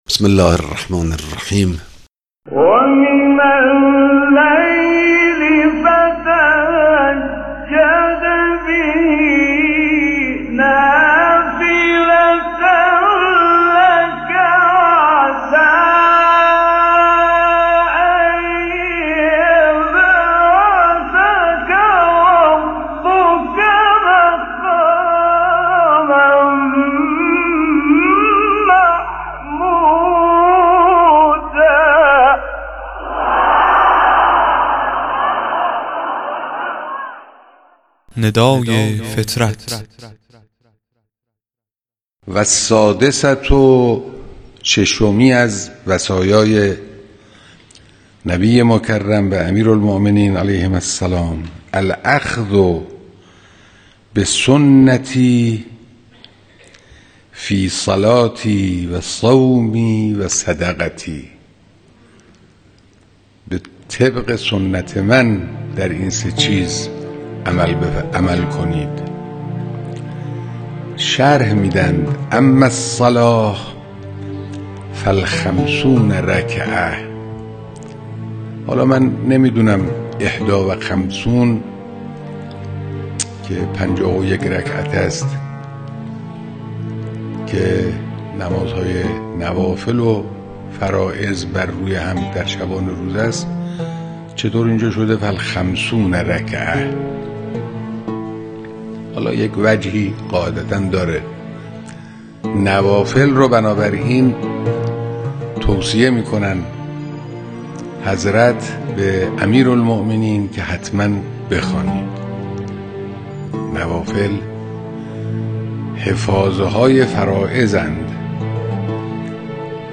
توضیح مختصر درباره ترمیم نمازهای واجب توسط نمازهای مستحبی و نوافل را از زبان امام خامنه ای مدظله العالی بشنوید.
موعظه، یاد خدا، نافله، حضور قلب، امام خامنه ای